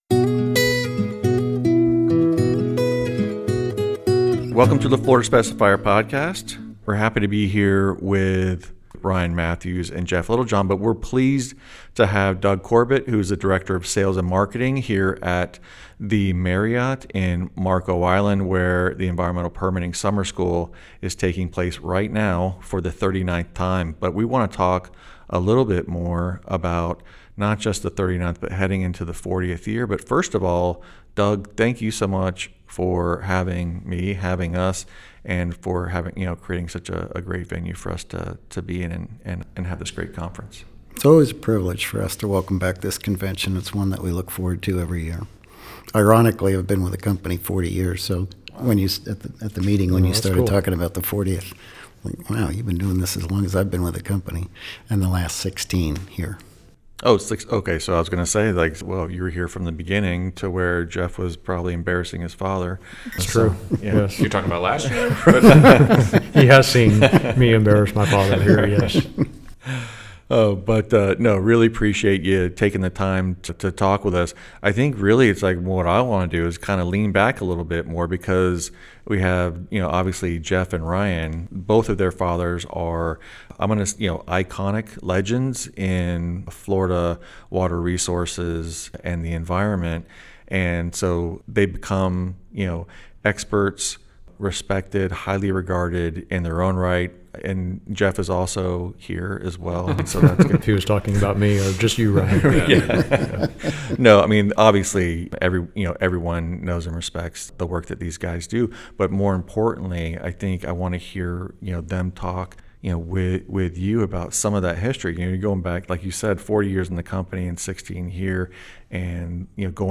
Together, they reflect on the rich history of the Environmental Permitting Summer School, now in its 39th year and gearing up for its 40th anniversary. The conversation blends personal stories, family legacies, and the special connection that so many attendees share with Marco Island.